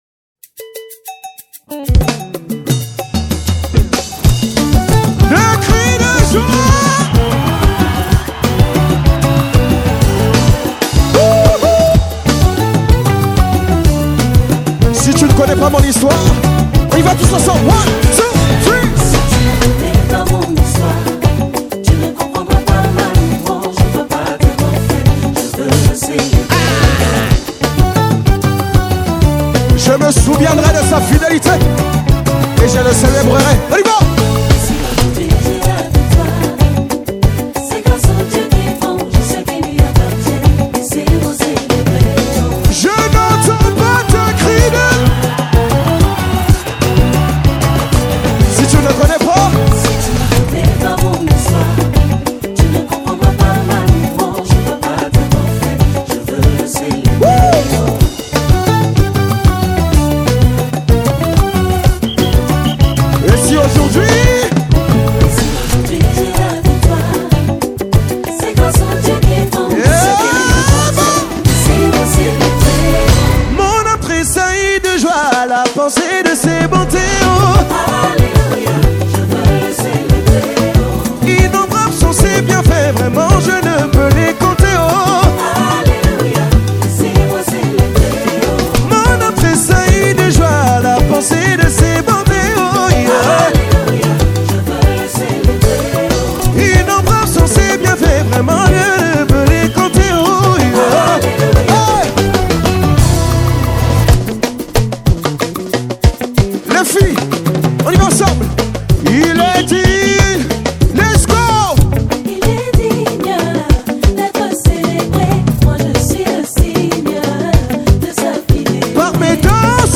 Congo Gospel Music
Contemporary worship leader, songwriter and lyricist